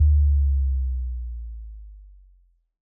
Bass Power Off.wav